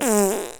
Fart.wav